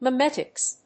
/mɛˈmɛtɪks(米国英語)/